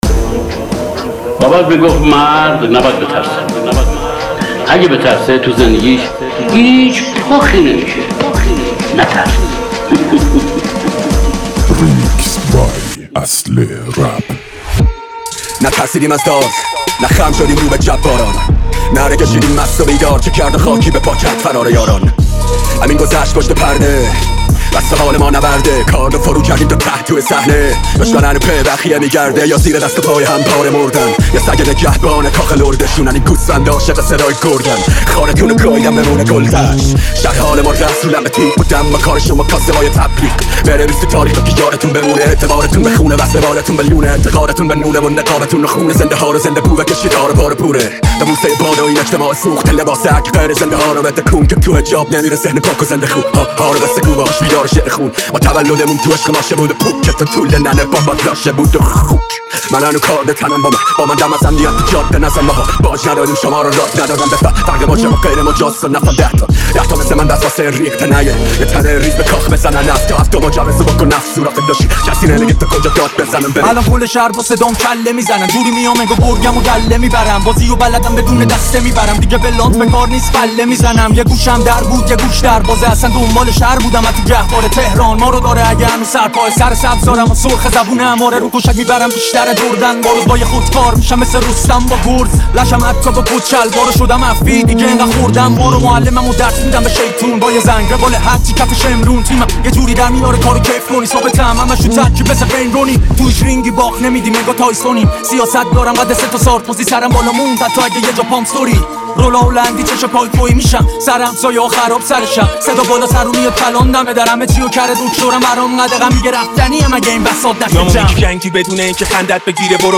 تک آهنگ